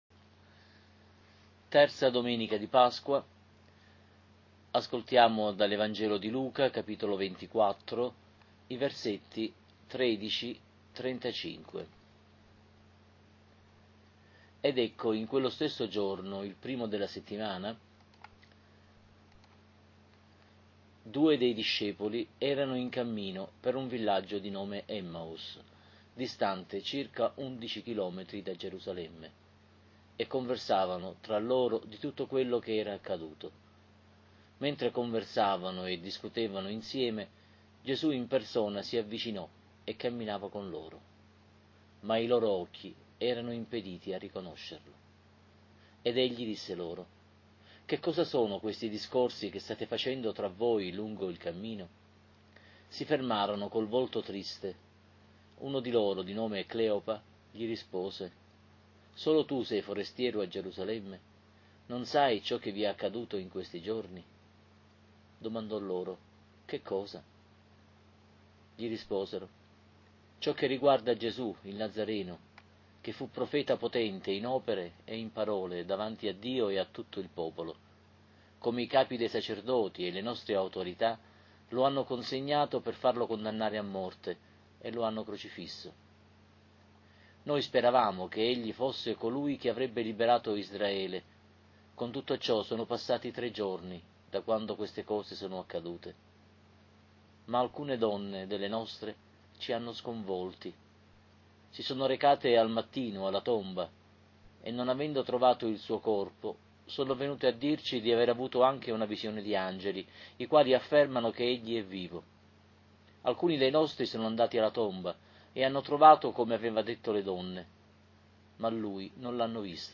Lectio divina Domenica «DI ÈMMAUS», III di Pasqua A